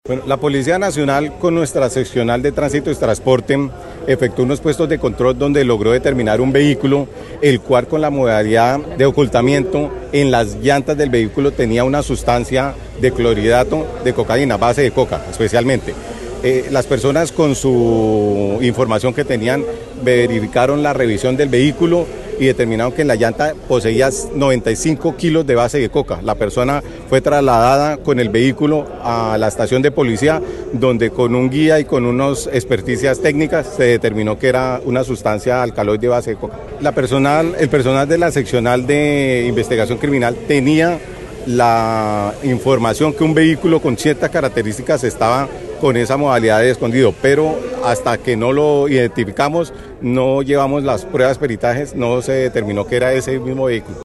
De acuerdo con el coronel Gosser Freddy Rangel, comandante de la Policía Caquetá, las investigaciones preliminares, indican que, el capturado haría parte de una estructura criminal dedicada al tráfico de sustancias ilícitas, con rutas que facilitan la distribución de alucinógenos hacia distintos países del continente para su comercialización.
CORONEL_GOSSER_RANGEL_COCA_-_copia.mp3